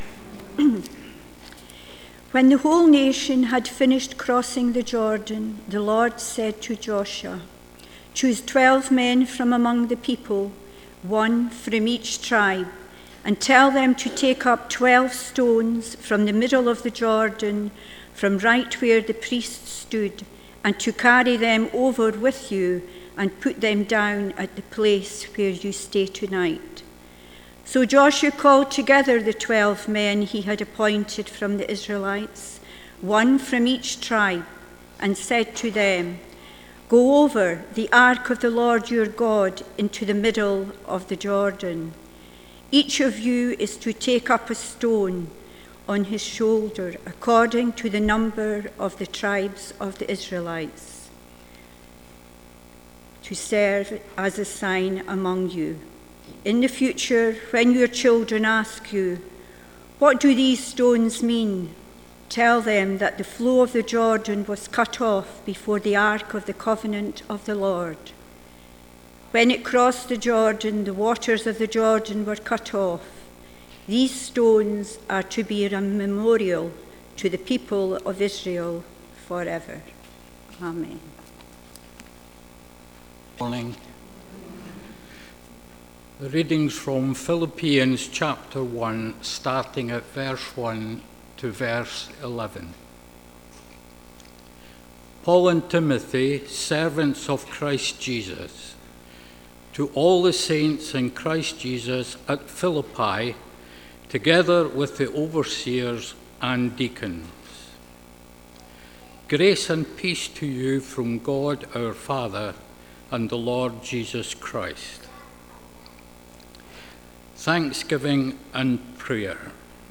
1-11 Service Type: Sunday Morning « Jesus is the way Craigowl Church